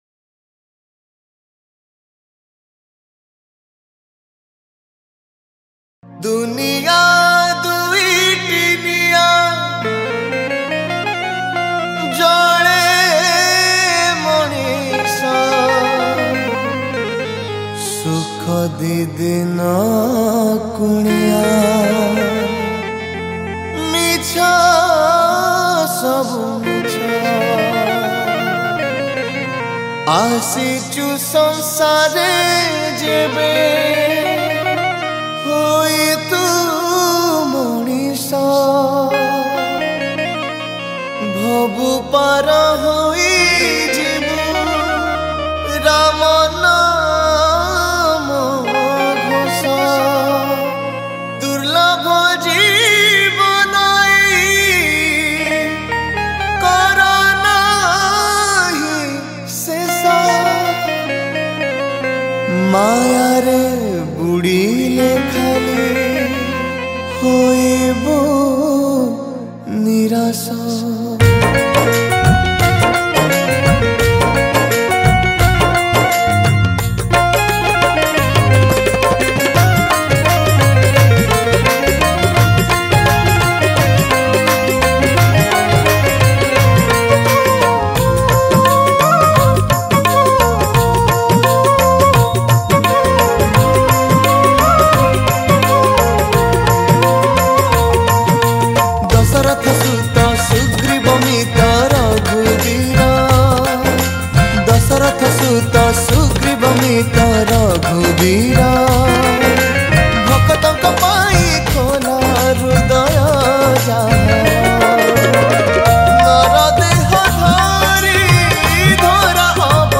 Category : Pana Sankarati Special Bhajan